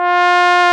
Index of /90_sSampleCDs/Roland L-CDX-03 Disk 2/BRS_Bs.Trombones/BRS_Bs.Bone Solo